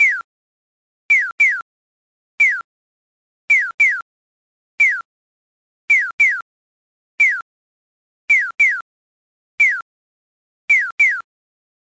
音響式信号機の色々
この「ピヨピヨカッコー」は異種鳴き交わし方式と言って横断する方向によって「ピヨ」と「カッコー」を使い分けることで渡る方向が判断できるようになっています。
「ピヨ」と「ピヨピヨ」 に音を変えて進行方向が判断できるように工夫されています。